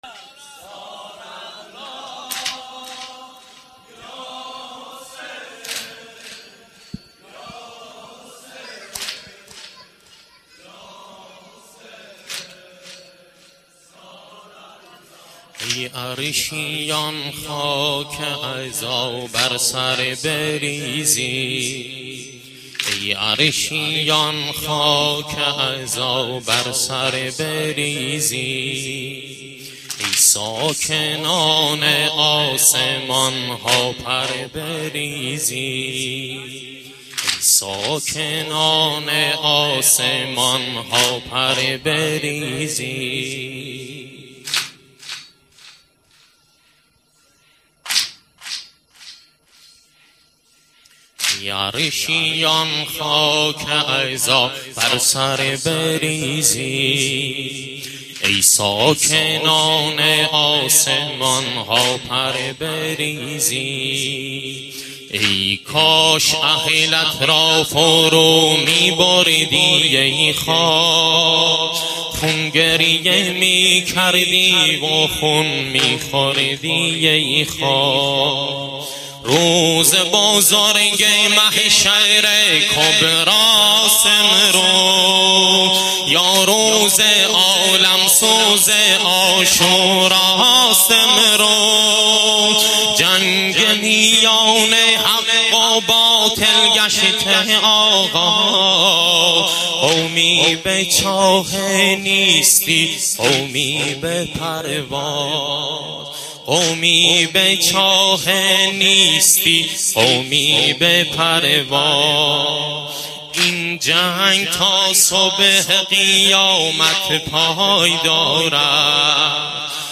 واحد - ای عرشیان خاک عزا بر سر بریزید